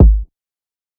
Metro House Kick.wav